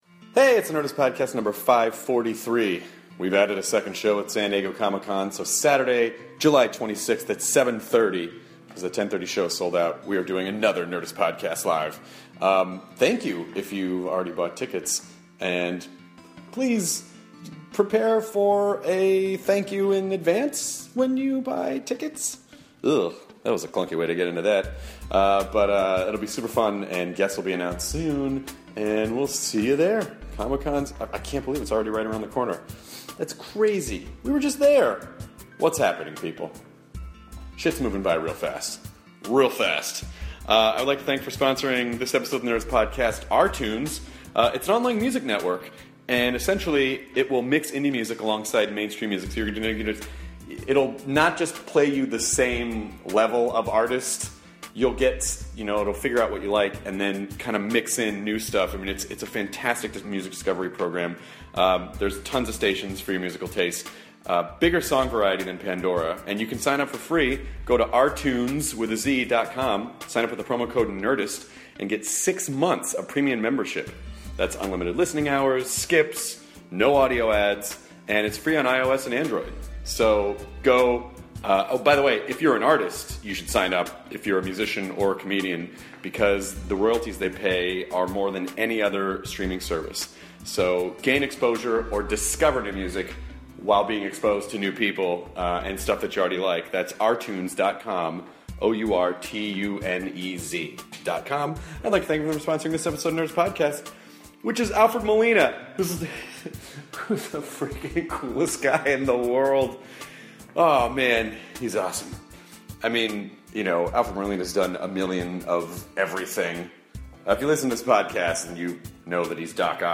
The wonderful and talented Alfred Molina sits down with Chris to talk about how he interprets the success of his career, playing the bad guy in so many movies, hanging out in Doctor Octopus gear while on set of Spider-man 2, and his new show, Matador!